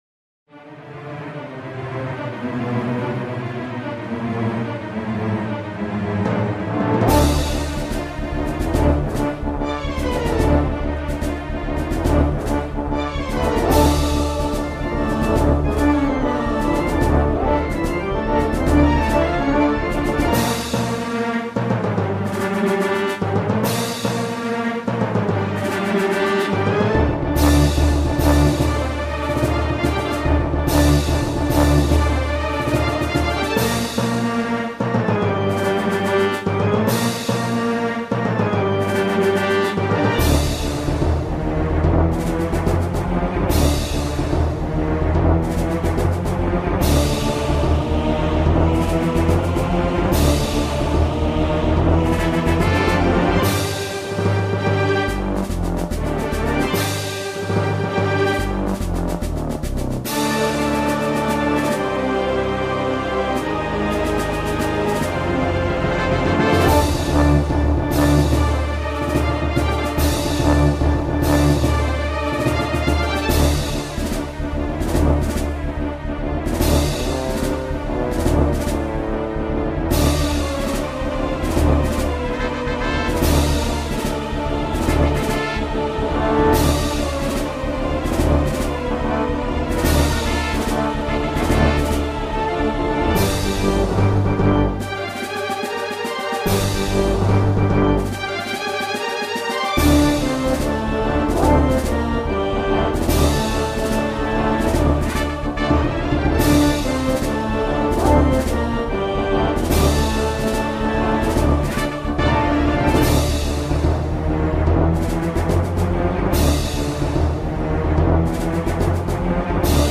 BGM: